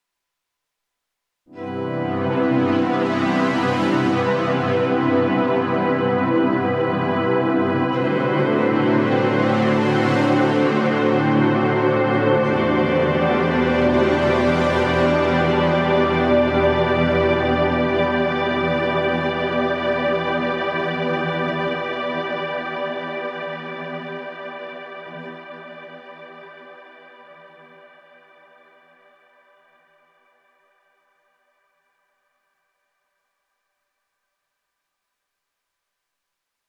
If anyone is interested, this is what this chord sounds like: